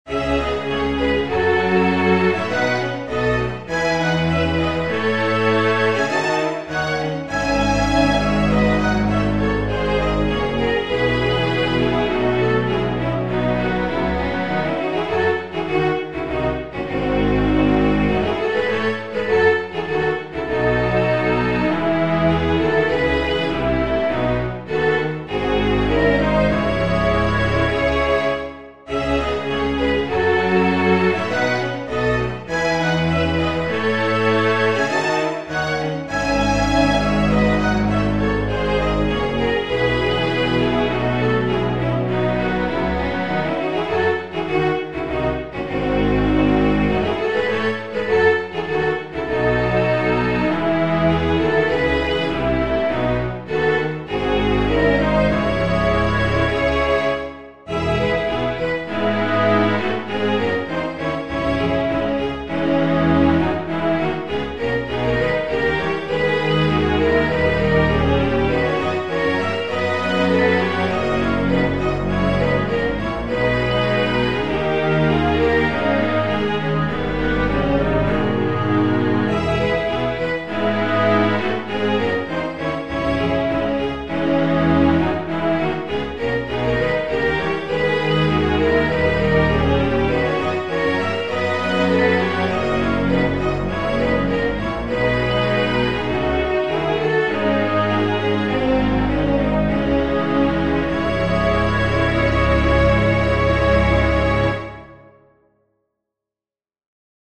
A Sarabande is a slow dance originating in Spain.